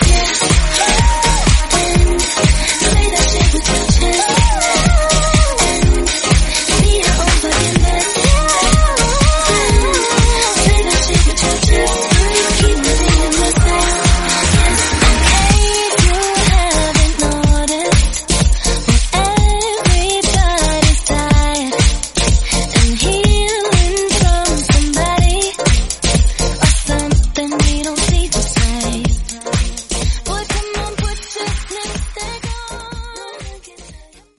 Yacht Rock. 125bpm.